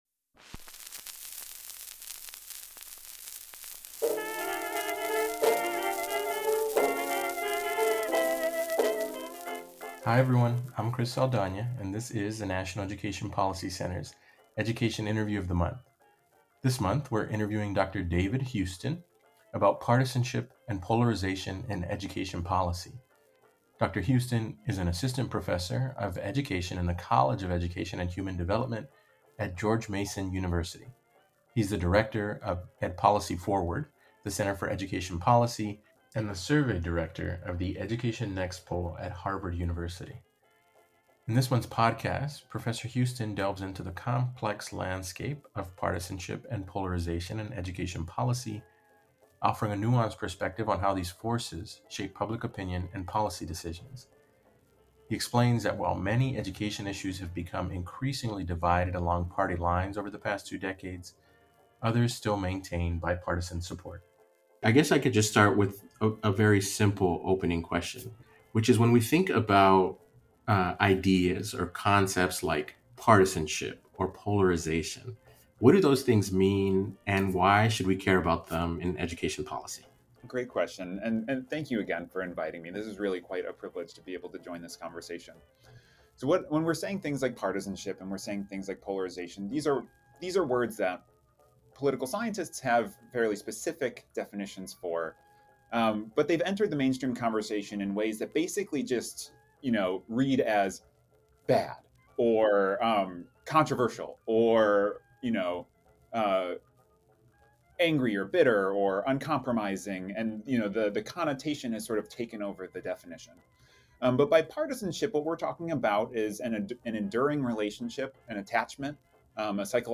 NEPC Talks Education: An Interview